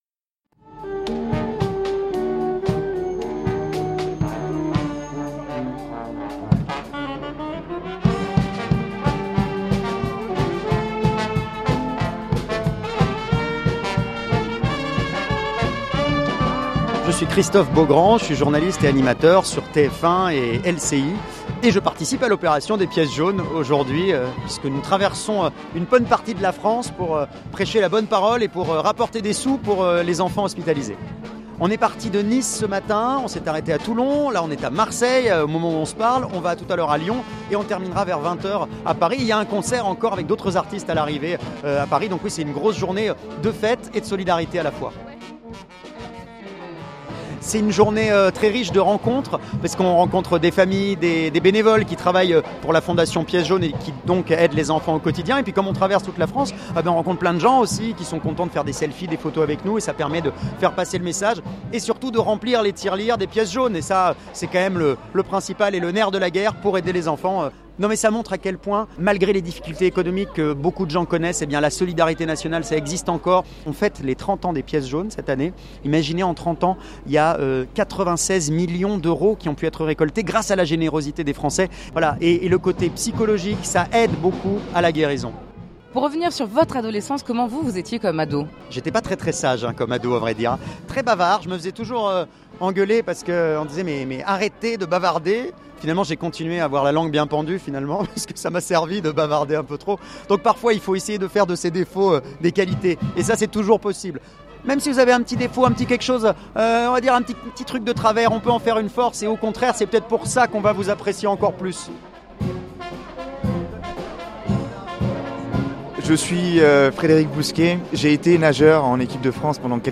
• Le reportage
C'est donc à la gare de Marseille St-Charles que nous avons retrouvé la fanfare, les animations, mais aussi les parrains de cette grande journée, tous mobilisés pour l'Opération Pièces Jaunes: une belle preuve de solidarité nationale qui dure depuis 30 ans!